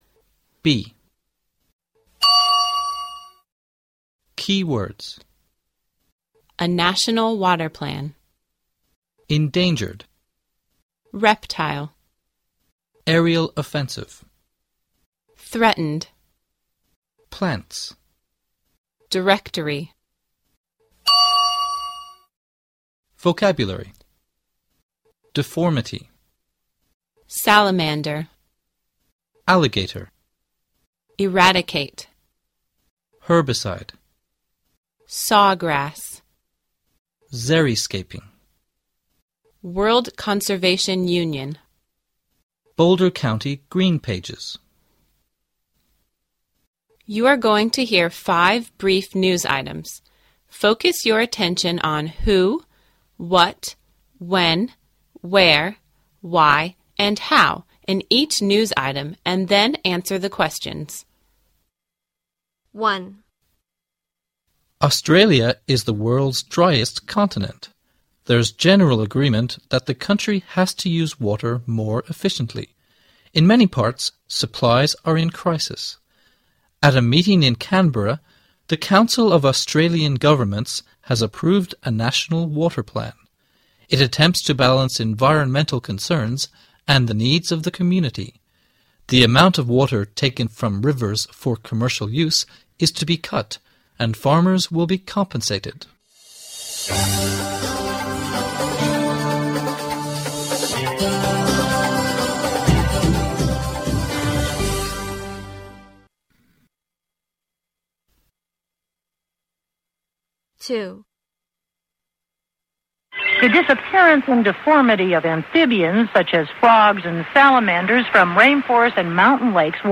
You're going to hear 5 brief news items.